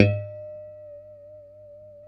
A3 PICKHRM1D.wav